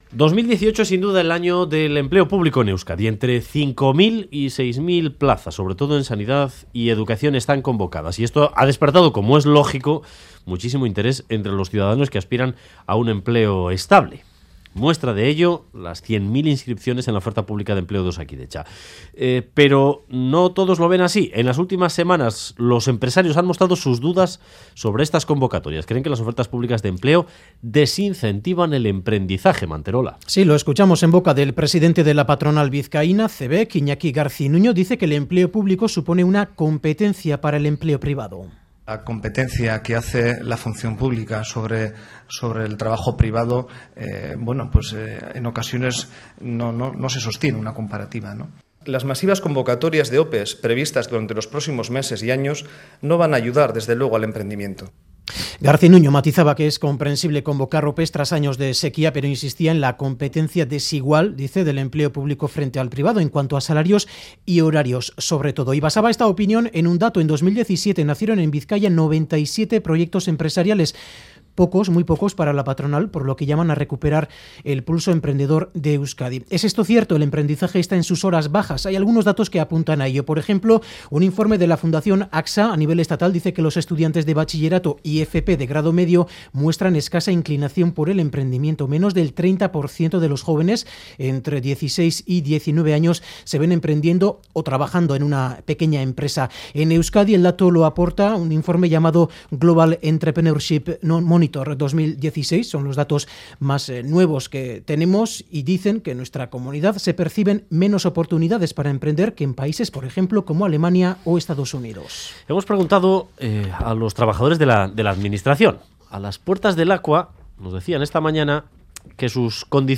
¿Funcionario o emprendedor? Debate en Boulevard de Radio Euskadi